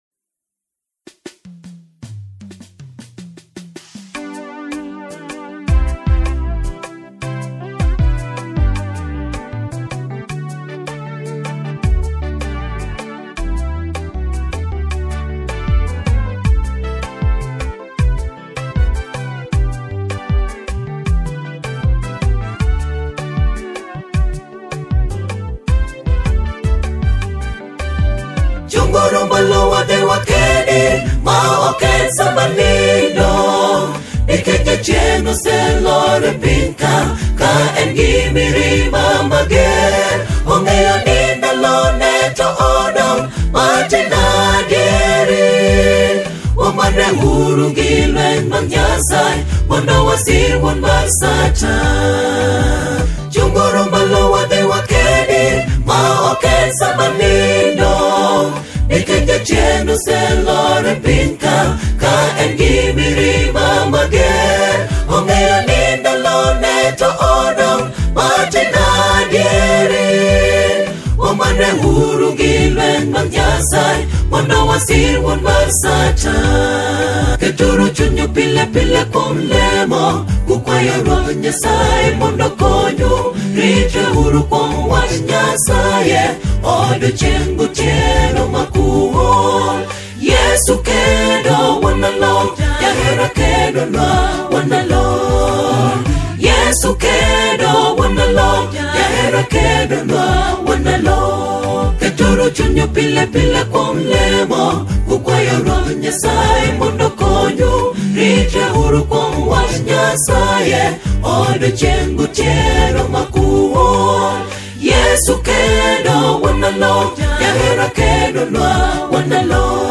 a soul-stirring and culturally resonant single